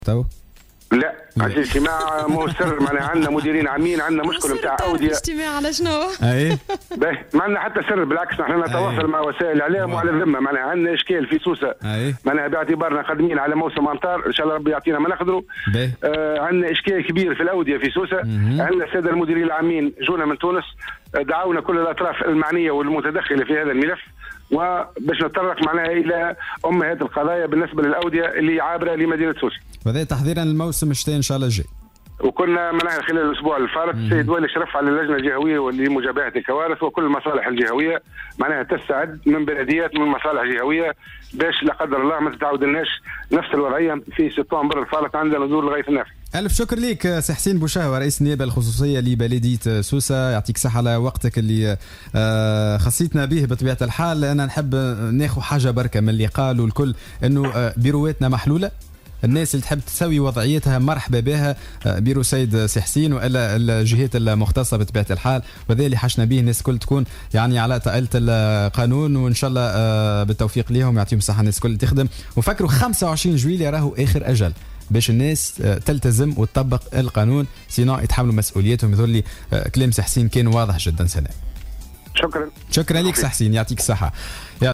أكد رئيس النيابة الخصوصية لبلدية سوسة حسين بوشهوة في مداخلة على الجوهرة 'اف ام' اليوم الثلاثاء 18 جويلية 2017 أن اجتماعا بمقر الولاية يجري صباح اليوم بإشراف والي الجهة وحضور عدد من المديرين العامين للتجهيز قدموا من تونس للنظر في في مشكل الأودية في الجهة خاصة مع اقتراب موسم الأمطار .